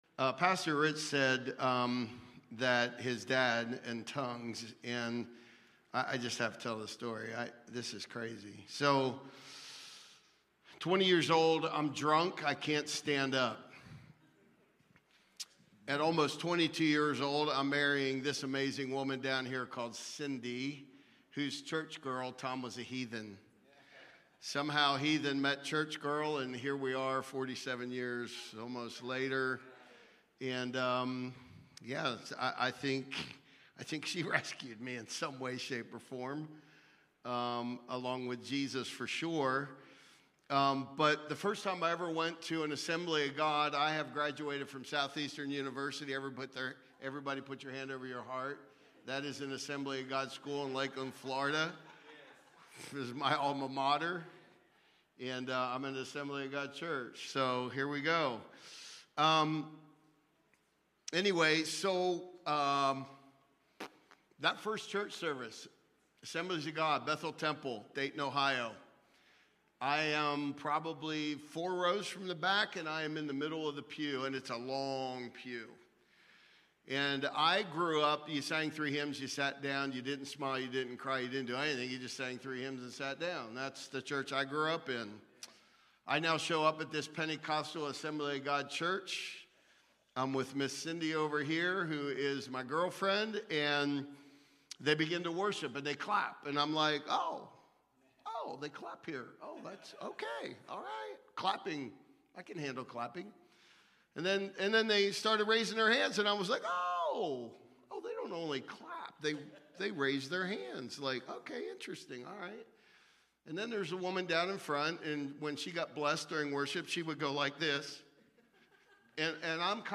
The Gospel , Salvation , Sanctification , Healing Watch Listen Save Cornerstone Fellowship Sunday morning service, livestreamed from Wormleysburg, PA.